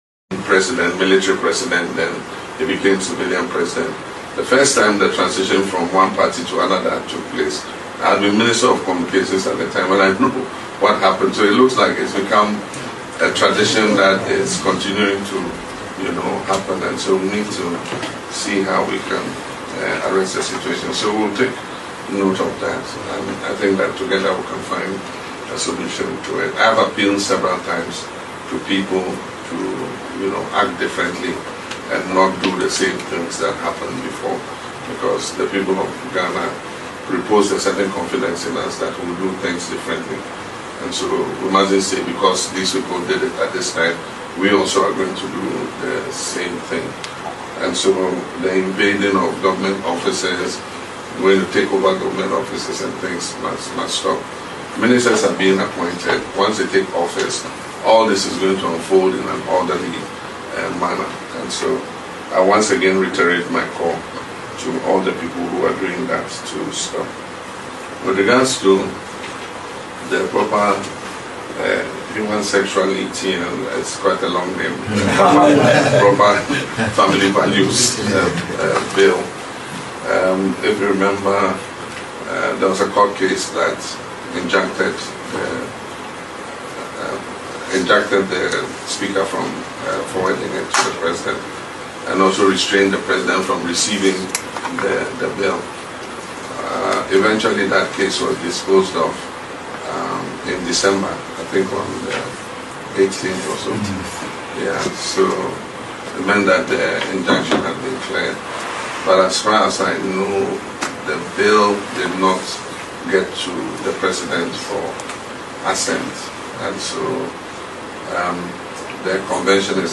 During a meeting with the Catholic Bishops, President Mahama highlighted the need for a more inclusive discussion on the matter, considering the bill’s lapse.